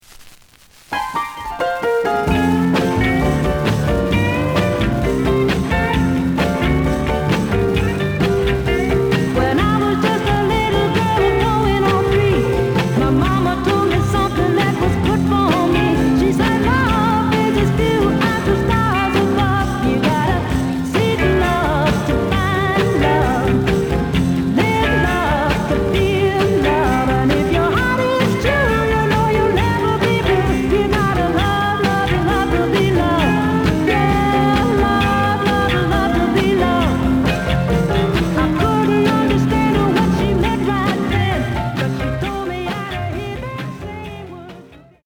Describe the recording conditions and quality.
The audio sample is recorded from the actual item. Some noise on both sides.)